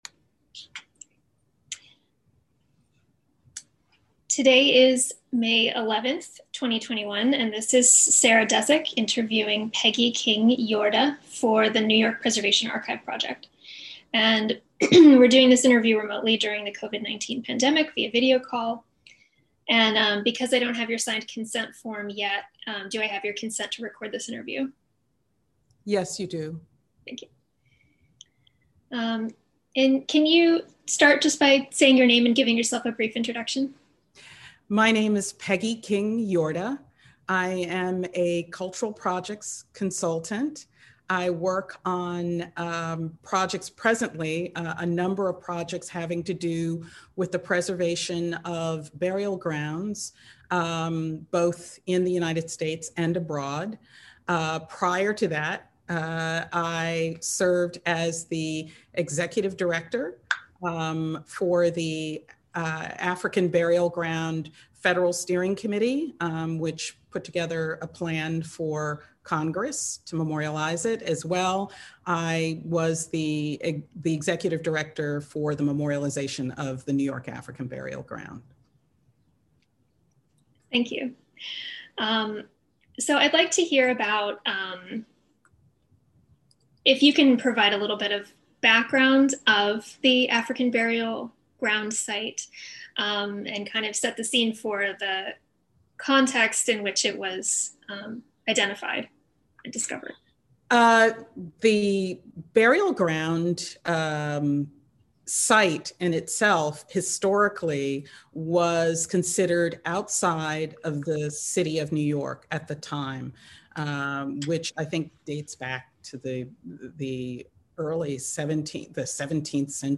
Gerry Trust Oral History Intensive